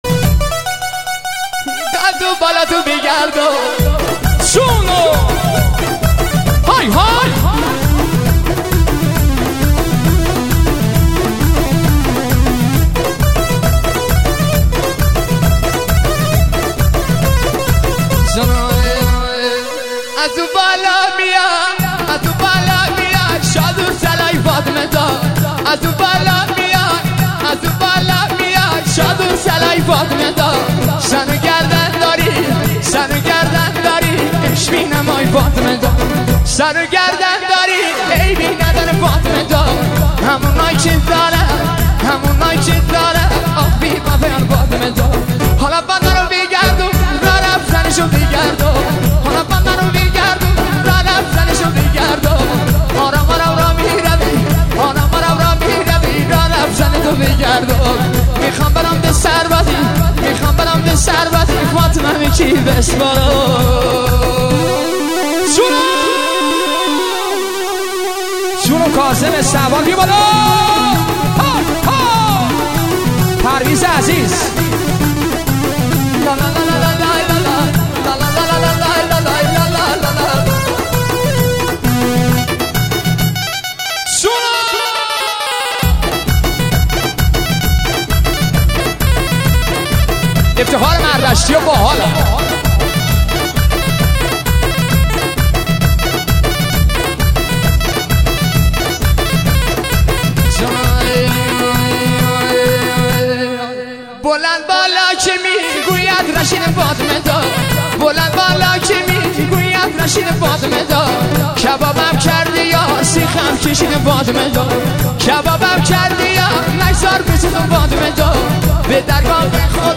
اهنگ شاد قر کمری
ریمیکس